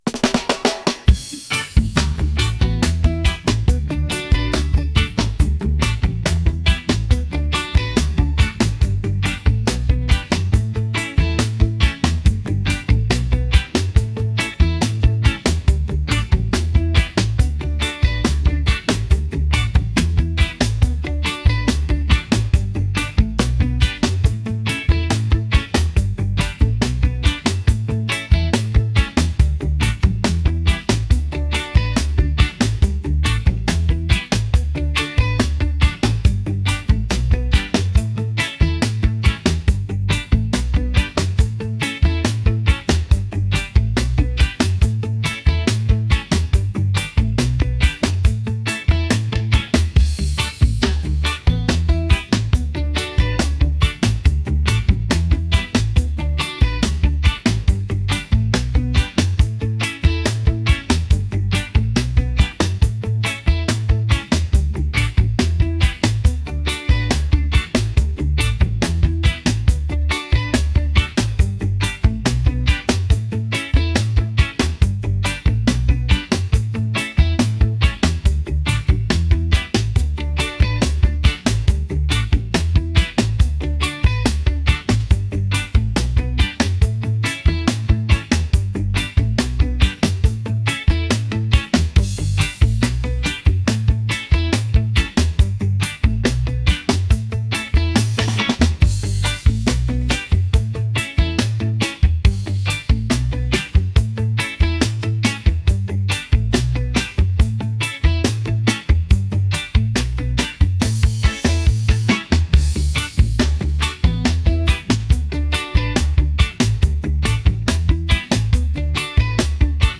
relaxed | upbeat | reggae